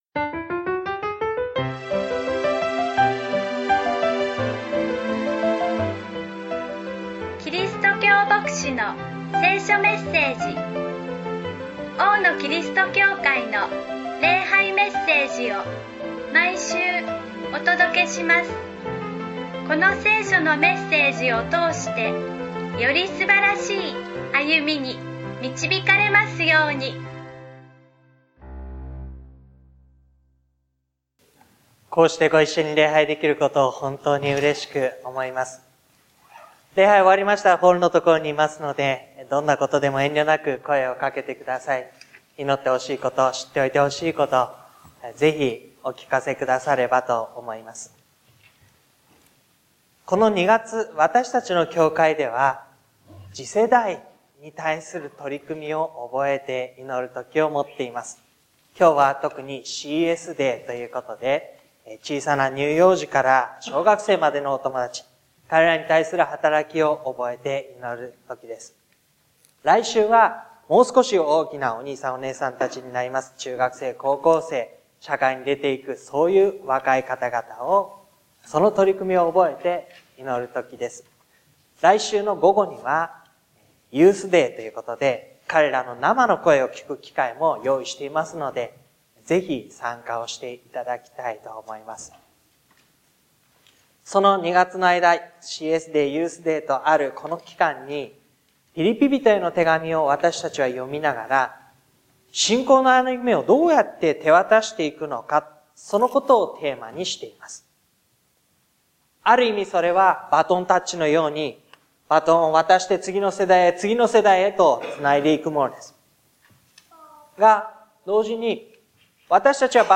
礼拝メッセージ 「偉大な先駆者にならって」